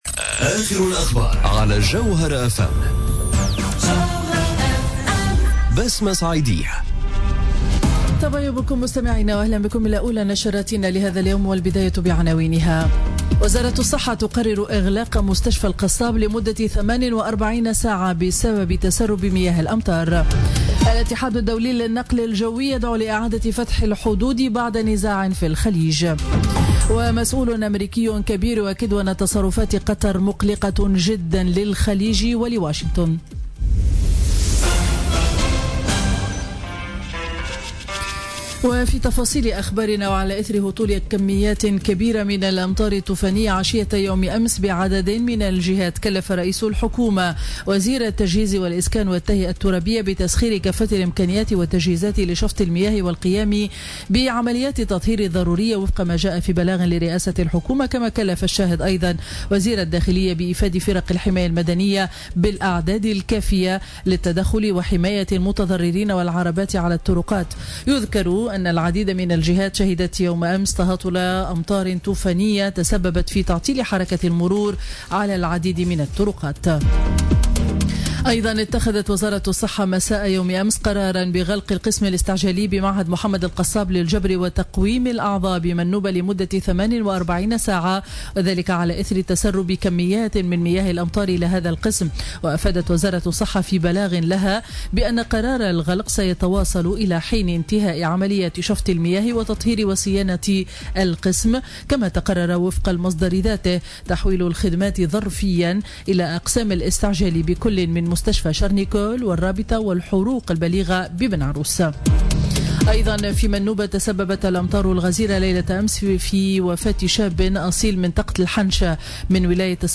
نشرة أخبار السابعة صباحا ليوم الثلاثاء 6 جوان 2017